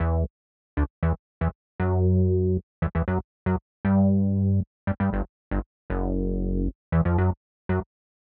11 Bass PT3.wav